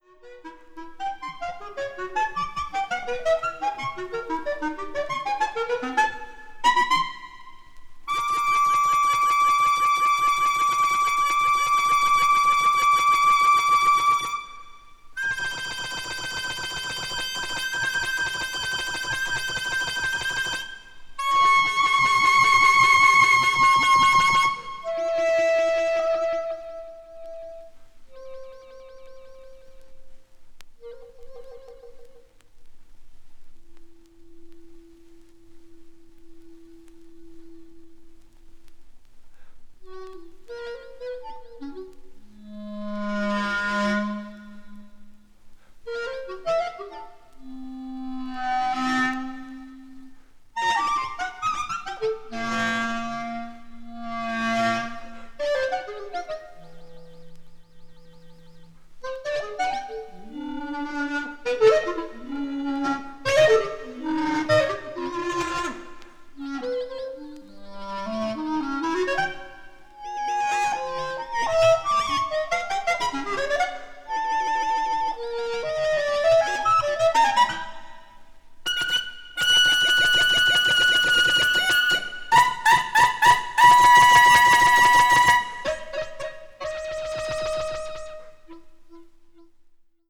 media : EX-/EX-(わずかなチリノイズ/一部軽いチリノイズが入る箇所あり)